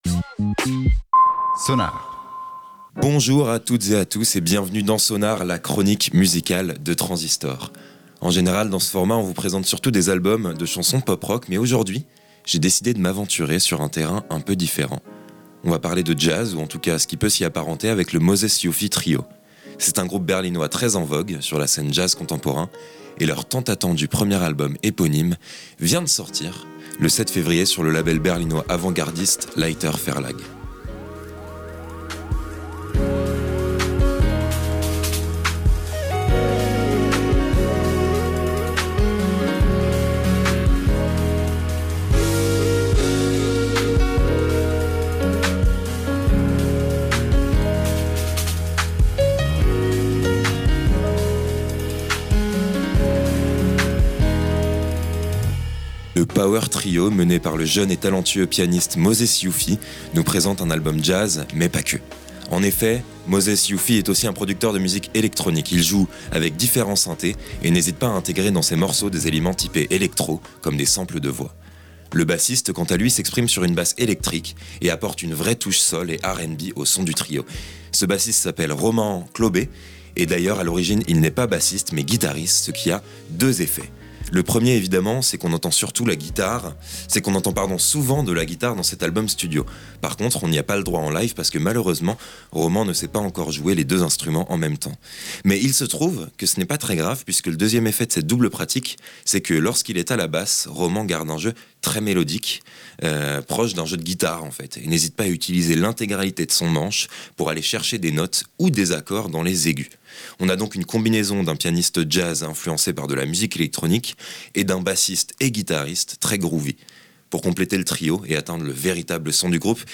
C'est un album fidèle au son du groupe, qui mèle jazz, funk, RnB et électro dans un tourbillon de sensations fortes.